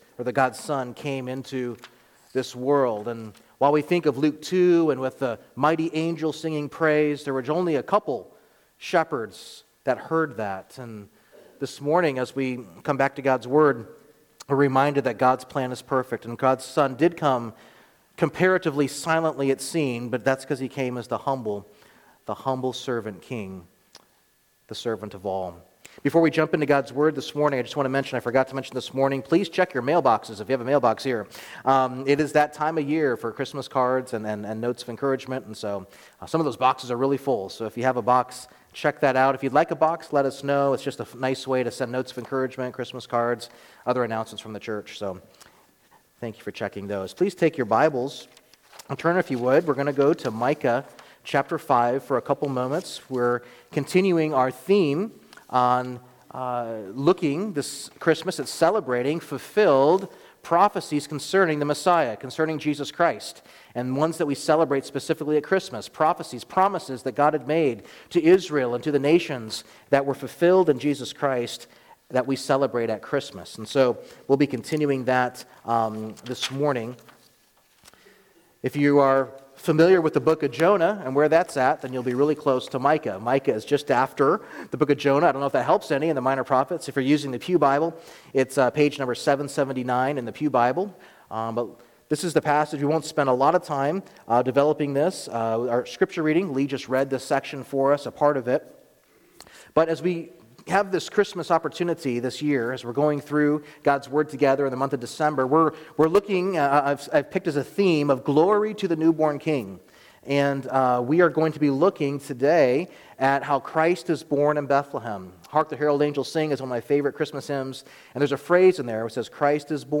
Sermons | Open Door Bible Church